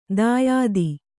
♪ dāyādi